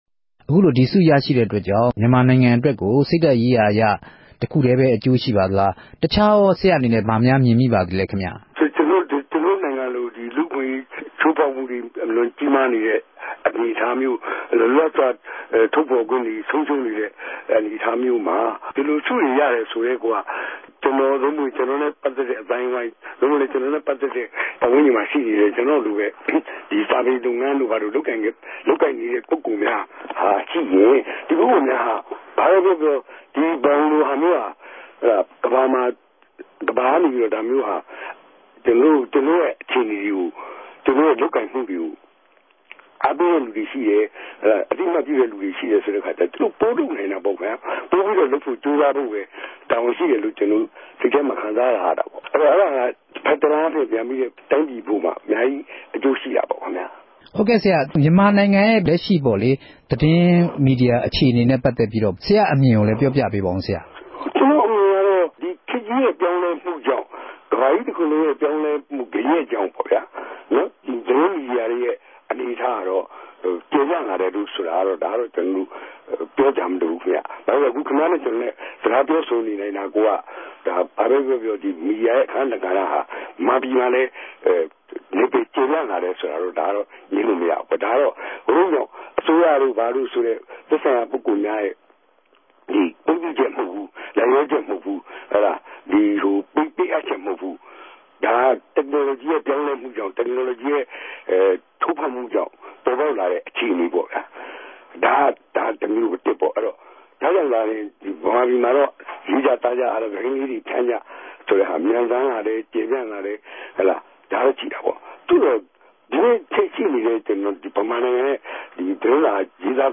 ဆုပေးပြဲသိုႛ တိုက်႟ိုက် ဆက်သြယ်မေးူမန်းခဵက်။